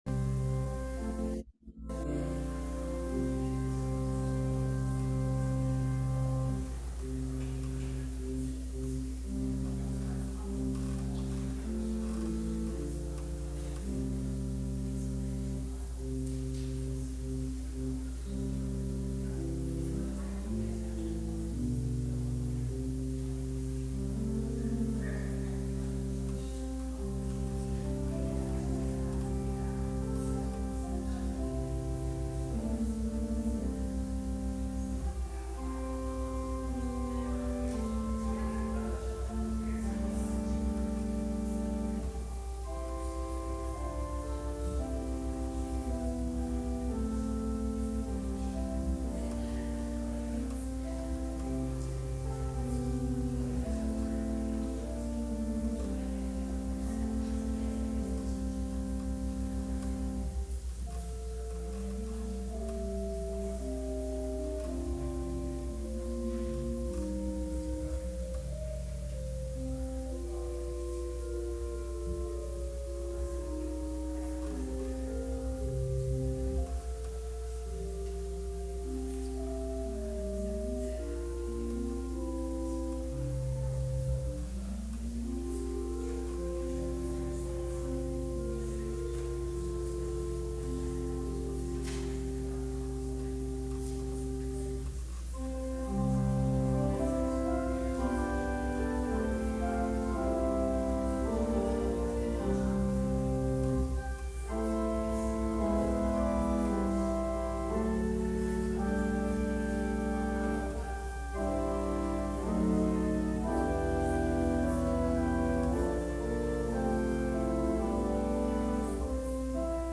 Worship Services | St Mark Lutheran Church